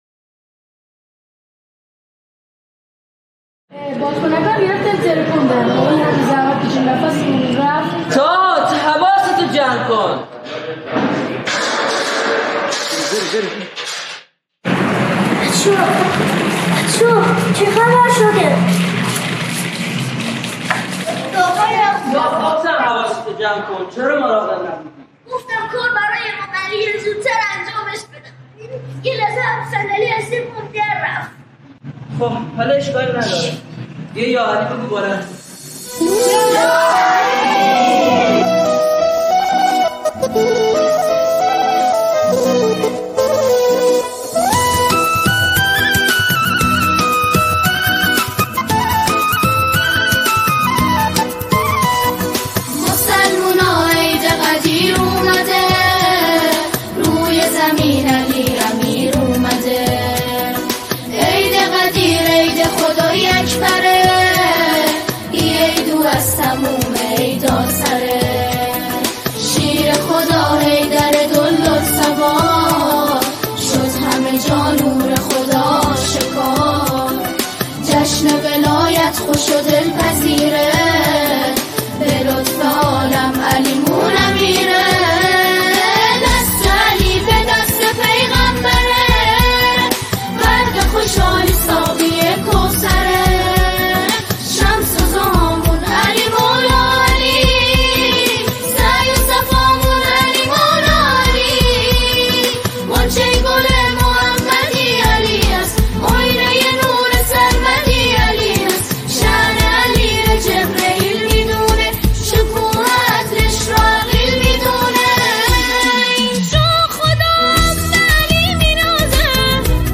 نماهنگ زیبا و دلنشین